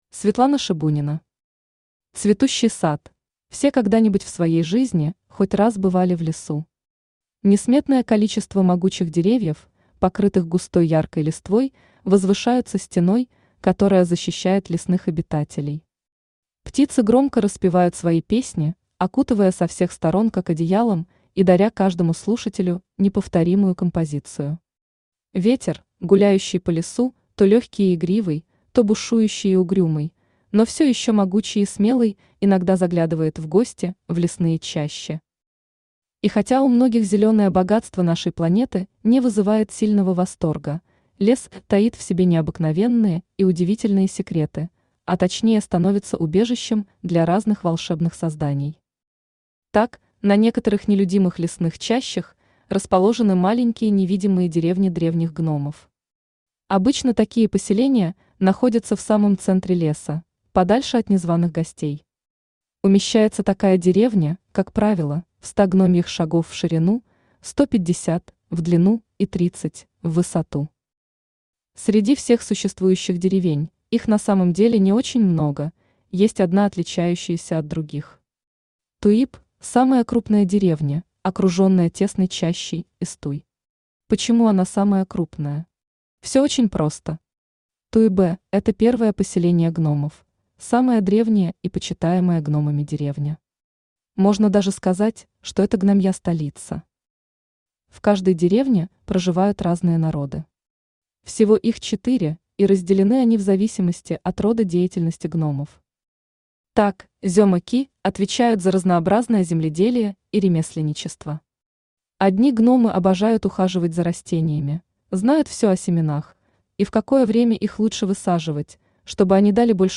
Aудиокнига Цветущий сад Автор Светлана Шебунина Читает аудиокнигу Авточтец ЛитРес.